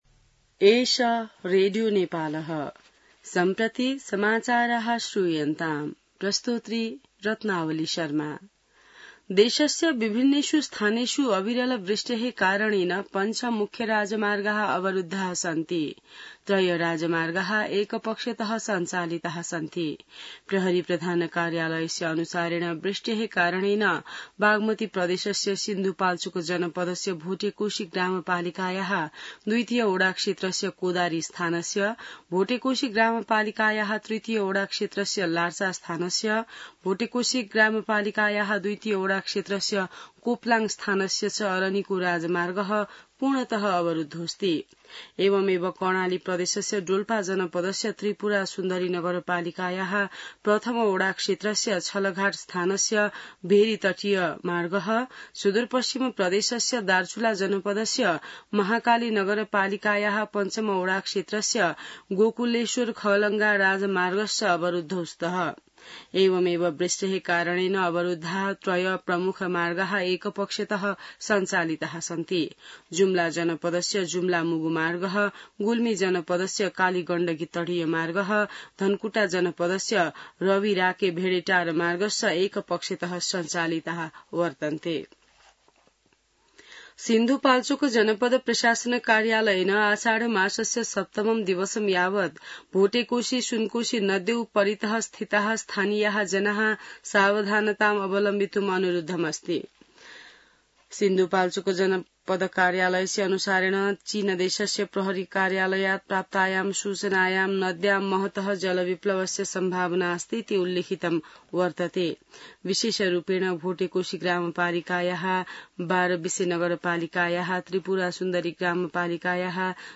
संस्कृत समाचार : ५ असार , २०८२